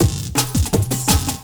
35 LOOP02 -L.wav